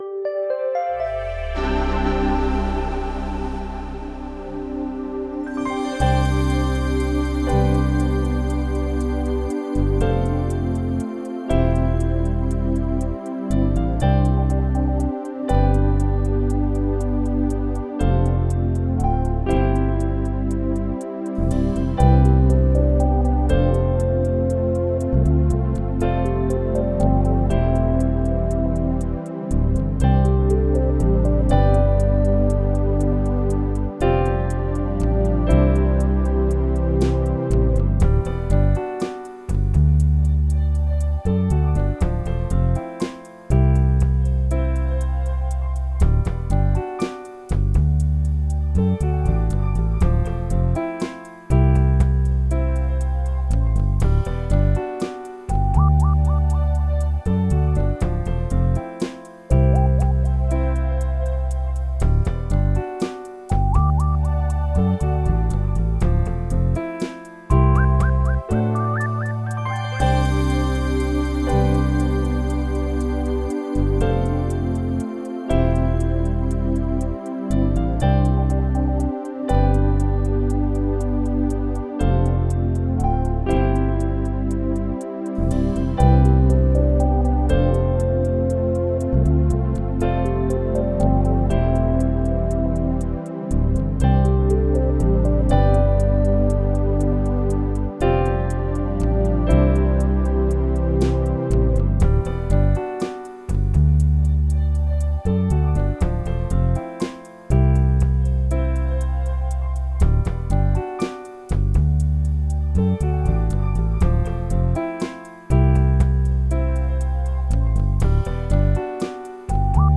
A frutiger aero song